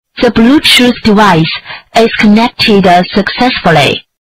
The Bluetooth Device Is Connected Successfully Sound (Chinese Speakers)
Kategori: Efek suara
the-bluetooth-device-is-connected-successfully-sound-chinese-speakers-id-www_tiengdong_com.mp3